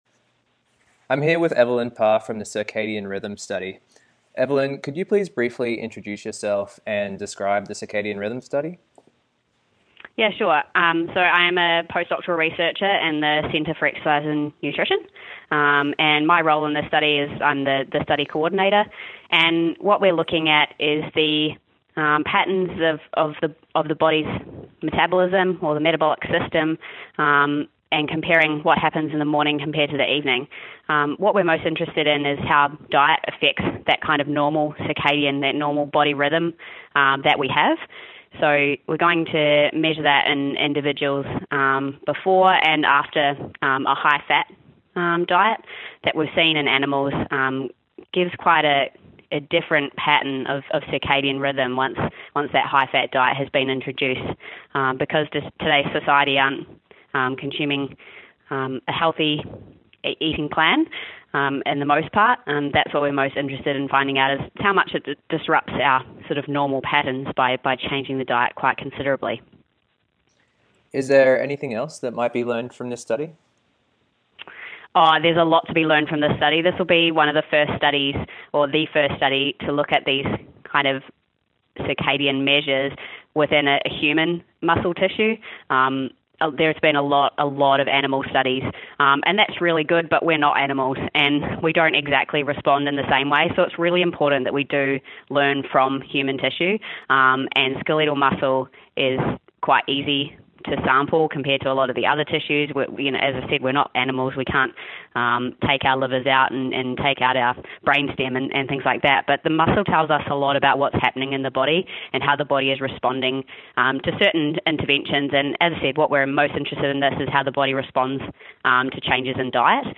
Researcher Interview